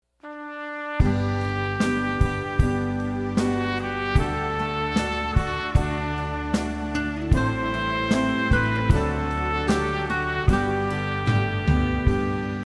￭ 알림음+벨소리 BBS
알림음